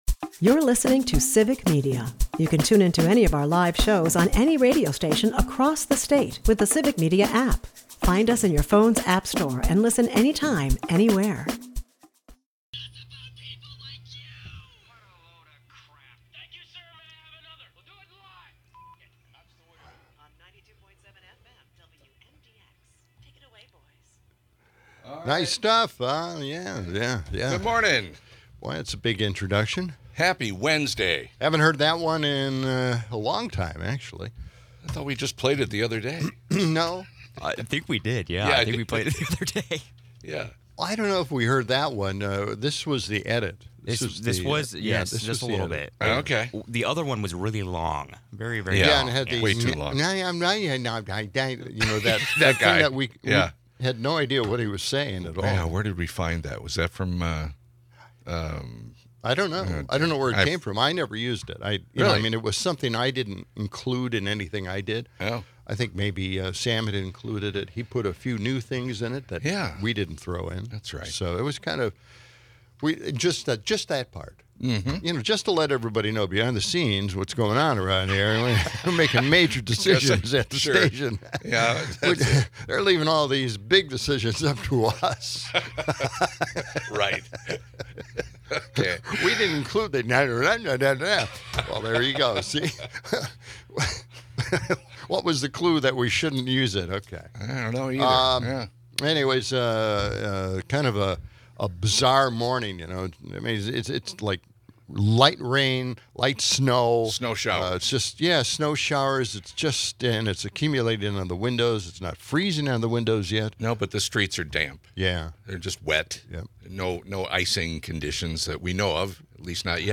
Hosts dive into the Genesis Mission, a controversial AI project merging tech with the Department of Energy, cloaked in secrecy and reminiscent of sci-fi nightmares. As snow showers hit, callers share weather woes and hunting tales. A fiery debate erupts over AI regulation and the political chaos surrounding Mark Kelly and Pete Hegseth.